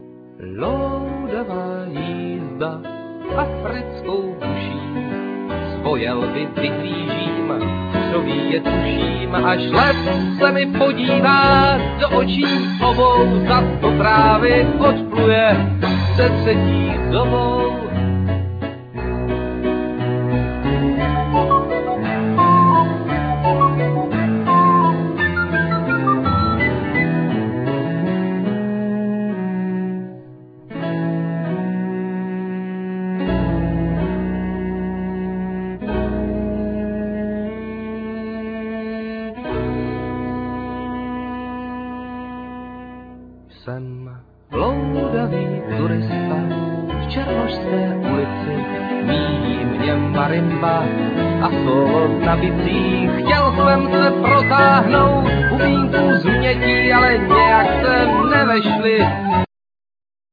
Piano,Keyboards,Guitar,Trumpet,Vocal,etc
Cello,Saxophone,Vocal,etc
Flute,Piano,Keyboards,etc